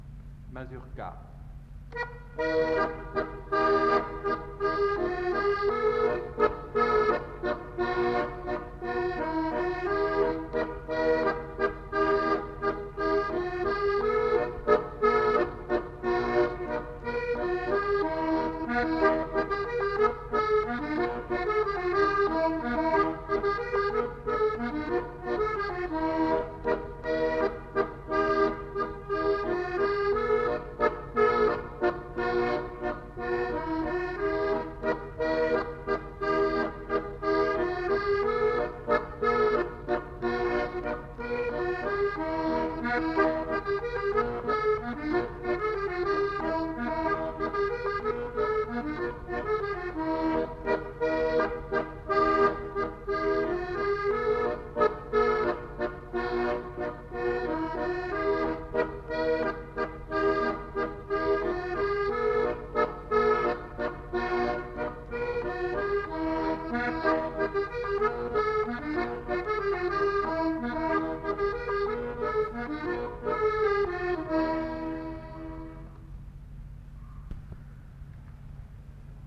enquêtes sonores
Mazurka